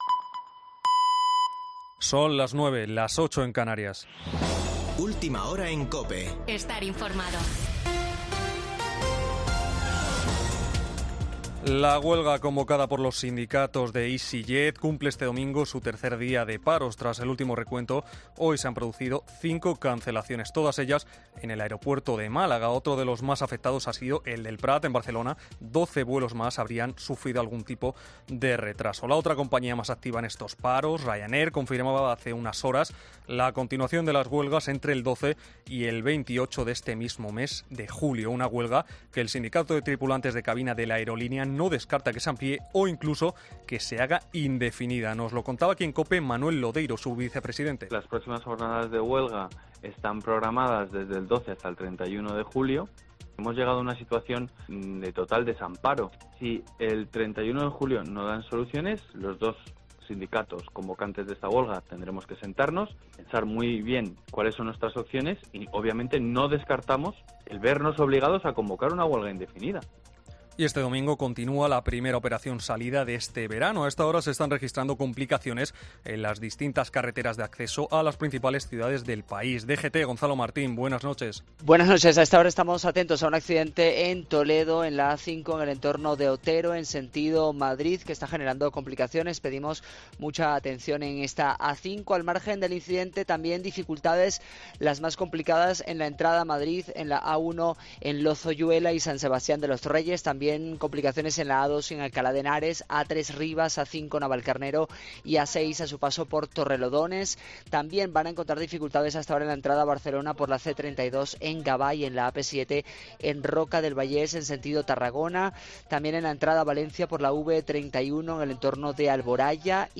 Boletín de noticias de COPE del 3 de julio de 2022 a las 21.00 horas